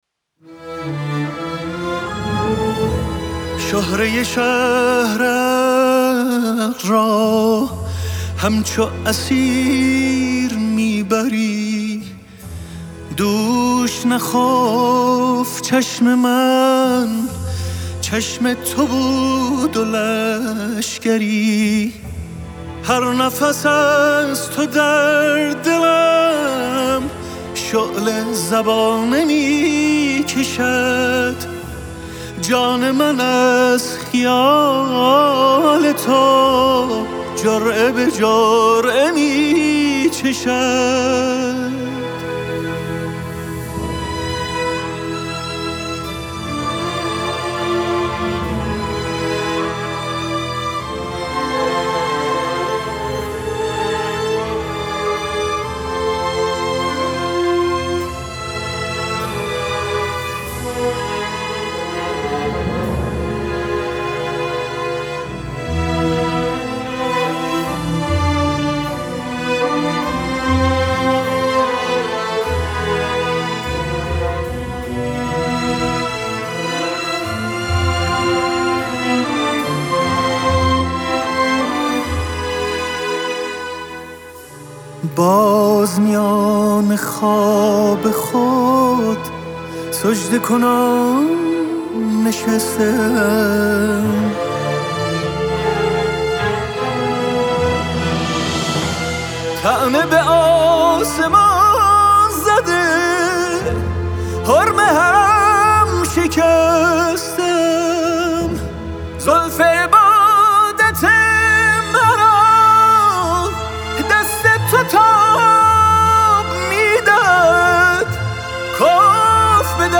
سبک : سنتی